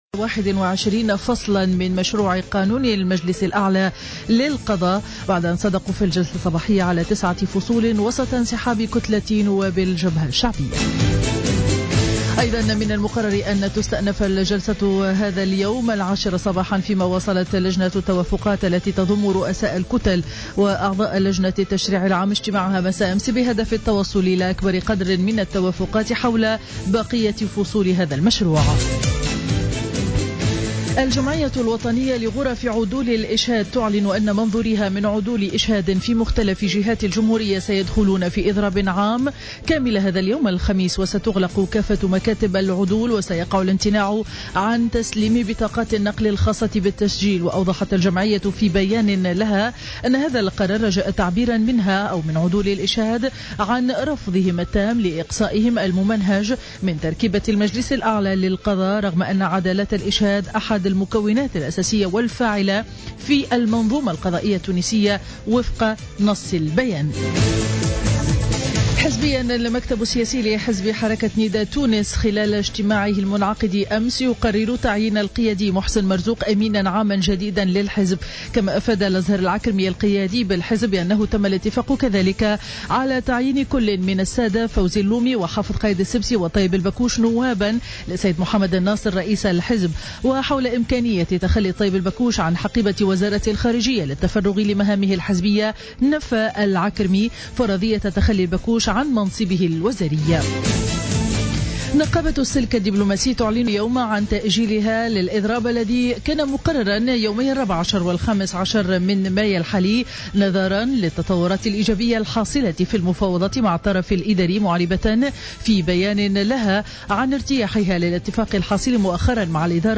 نشرة أخبار السابعة صباحا ليوم الخميس 14 ماي 2015